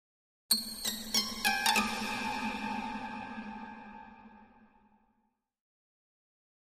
High Strings
Harmonic Plucks Harmonic Pad Mystic Harmonic Plucks Dissonant Arpeggio